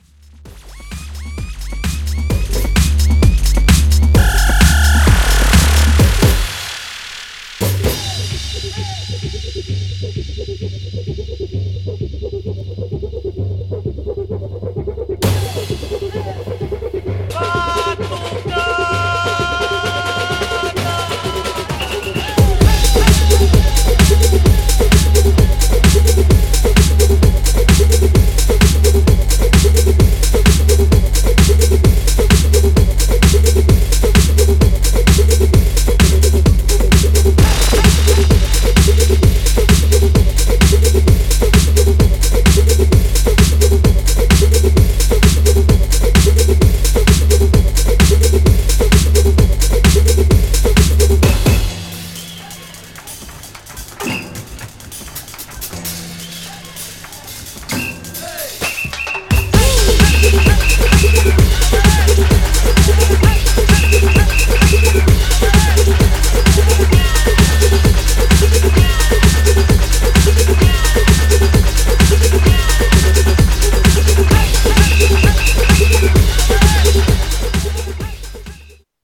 Styl: House, Breaks/Breakbeat